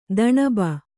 ♪ daṇaba